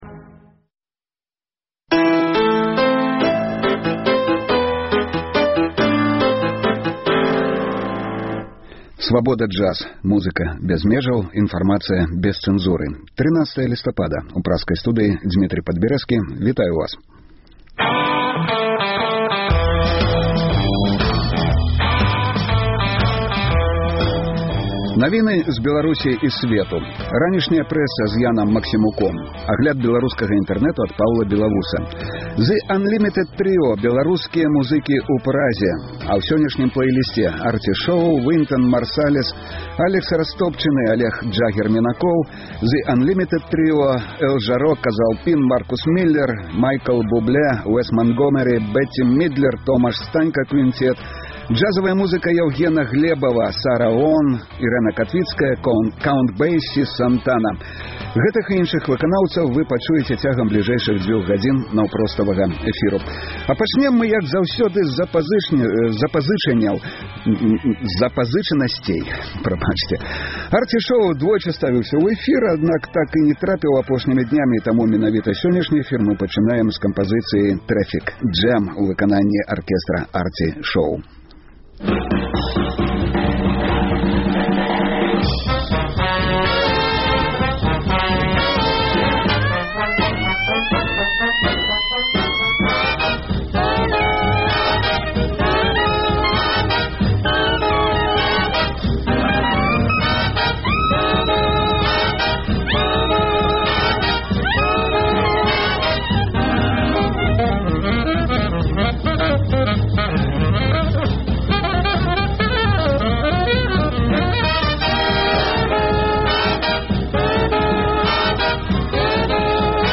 Слухайце ад 12:00 да 14:00 жывы эфір Свабоды!